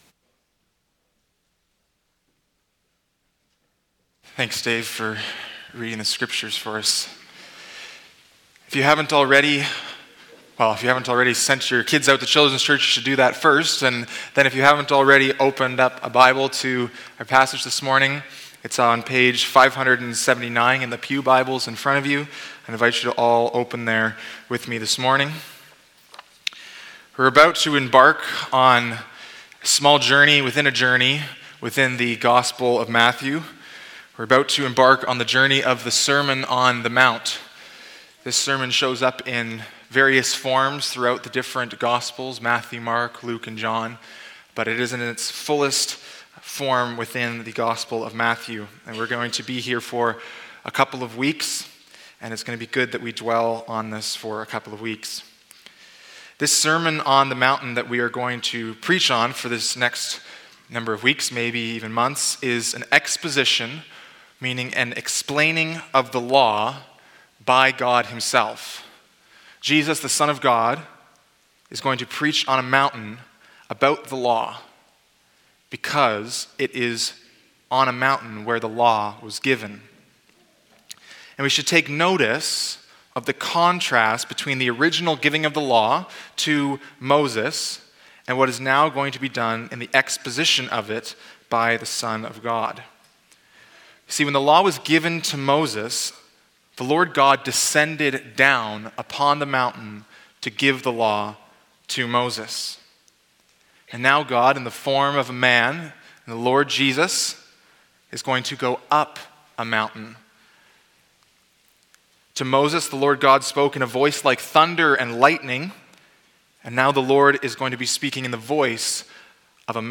Sermons | Bridgeway Community Church